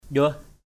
/ʄʊəh/ 1.